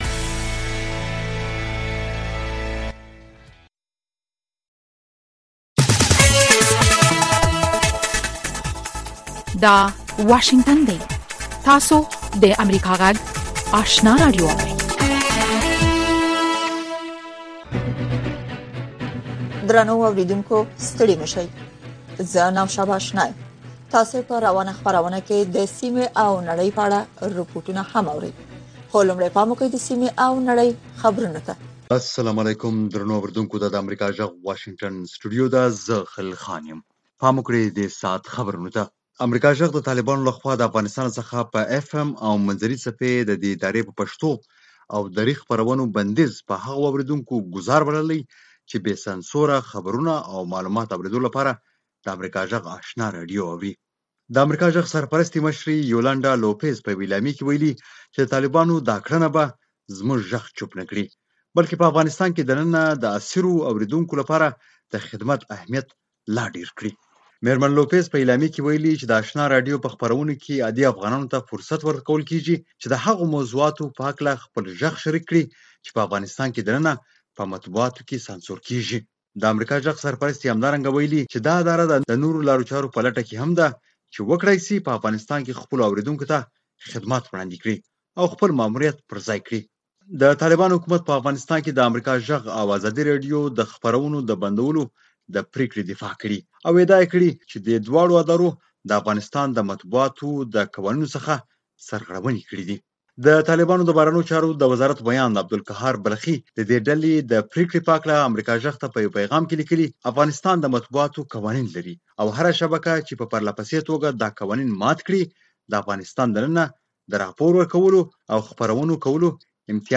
سهارنۍ خبري خپرونه